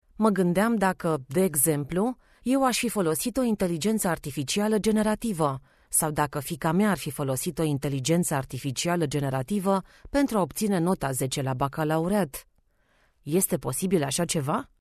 Female
Bright, Confident, Corporate, Friendly, Warm, Engaging, Natural
Neutral Romanian and English with Eastern European Accent
Radio_Commercial_Bank.mp3
Microphone: Neumann TLM 107